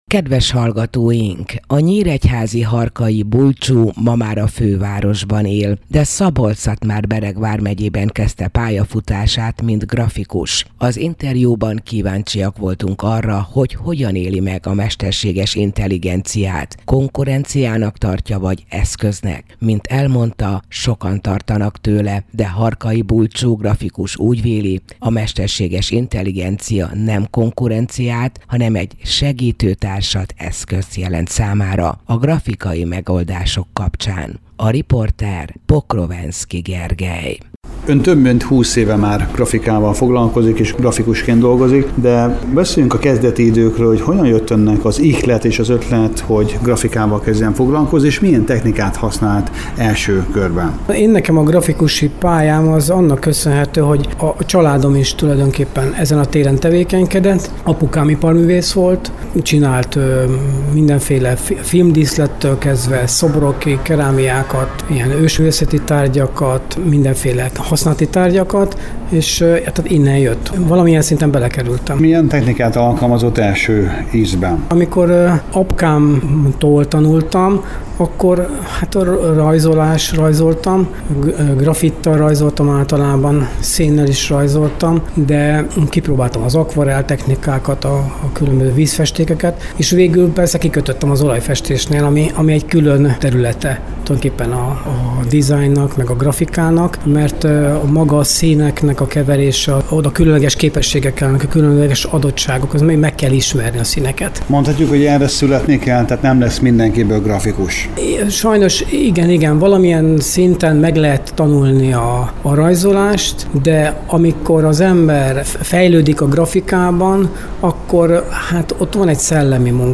Az interjúban kíváncsiak voltunk arra, hogy hogyan éli meg a mesterséges intelligenciát: konkurenciának tartja, vagy egy eszköznek? A következő beszélgetésből kiderül.